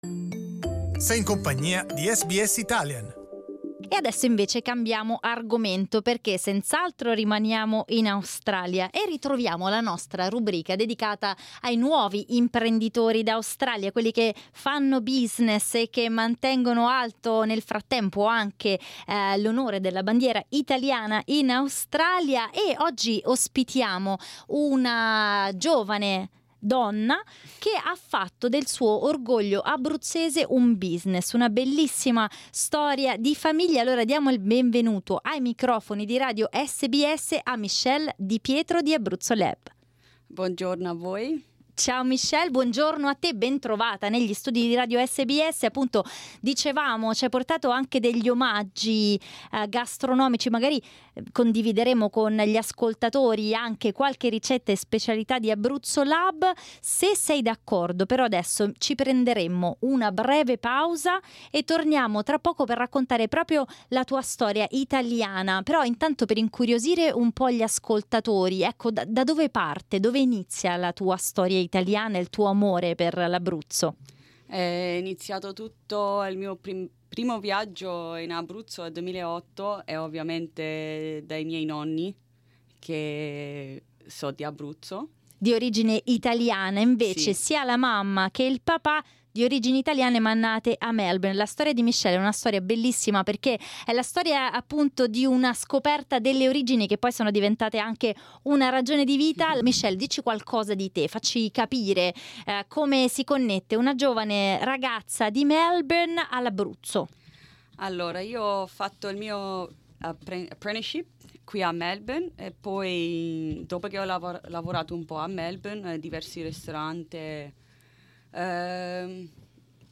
Ecco come ha sfruttato entrambi nell'intervista a SBS Italian.